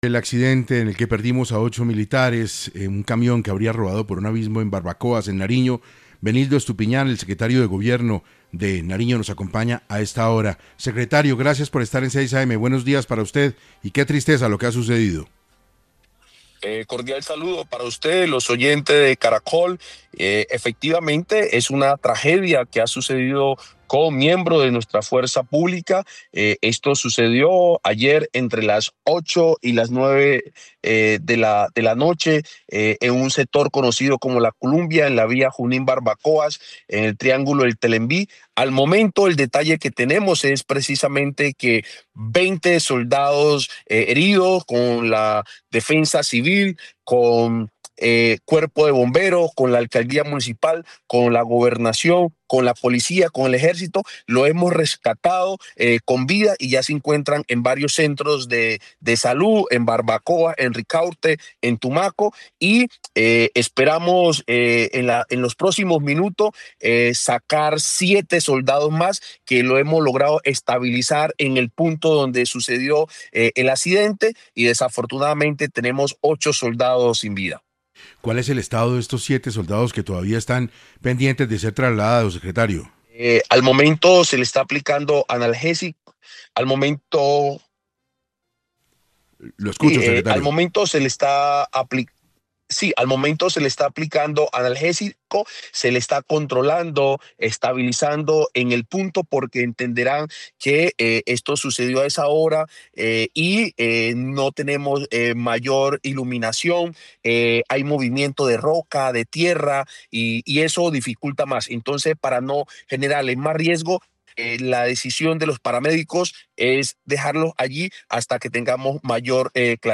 Benildo Estupiñán, secretario de Gobierno de la Gobernación de Nariño confirmó en 6AM que son cerca de 33 los soldados afectados por el accidente.
En 6AM de Caracol Radio, Estupiñán estuvo para hablar sobre cuál el estado de salud de los soldados accidentados en el departamento y el tema del levantamiento de los cuerpos de los fallecidos.